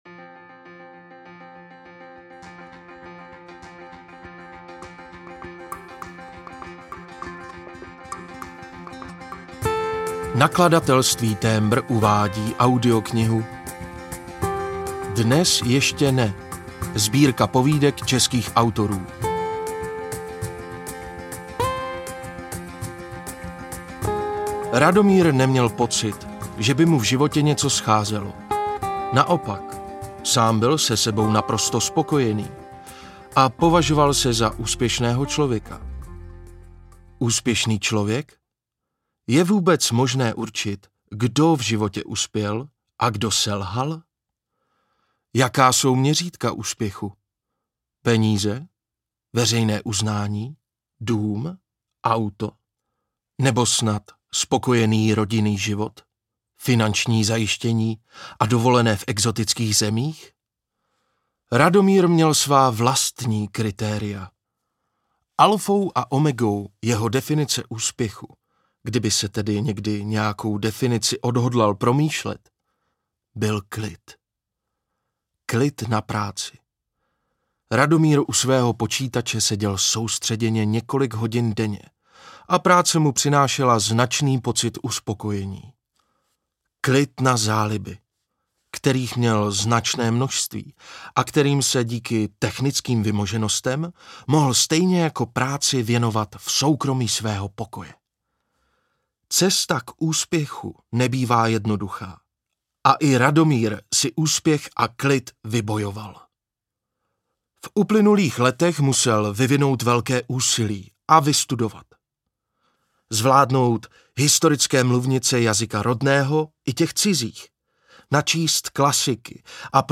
Dnes ještě ne audiokniha
Ukázka z knihy